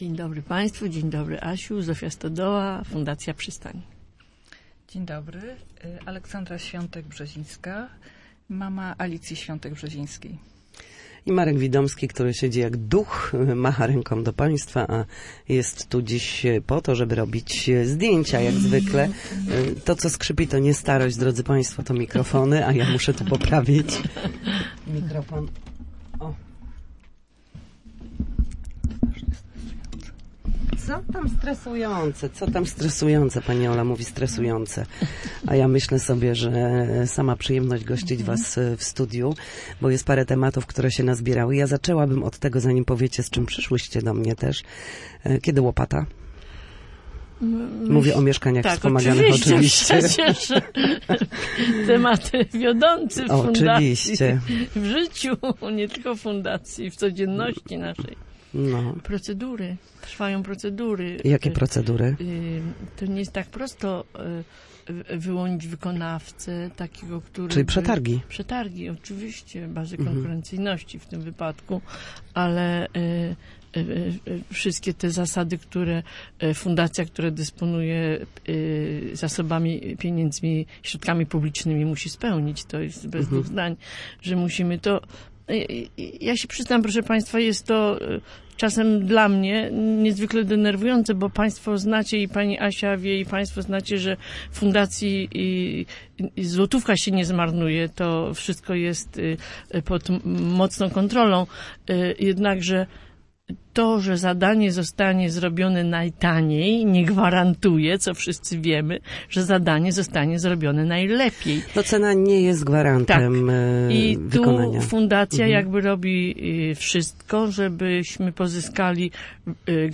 Na naszej antenie mówiły o działaniach instytucji, mieszkaniach wspomaganych, wsparciu asystentów i współpracy z Uniwersytetem Pomorskim